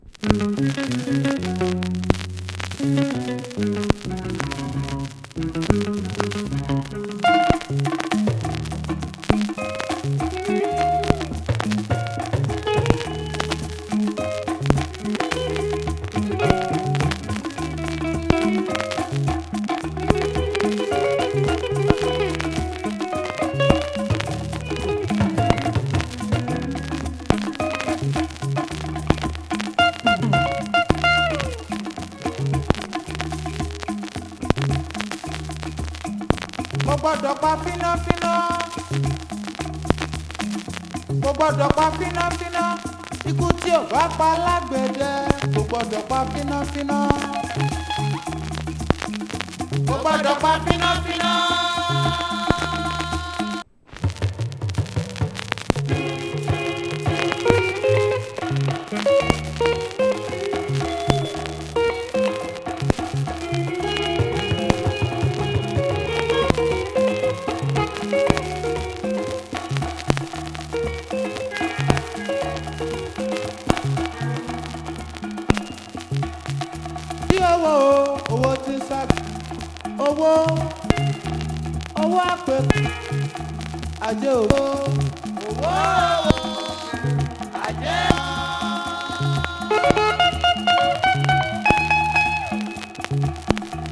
Jazzy Highlife